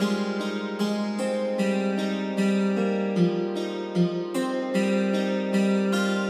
悲哀而蹩脚的吉他弹奏
描述：有利于民俗
Tag: 152 bpm Folk Loops Guitar Acoustic Loops 1.07 MB wav Key : Unknown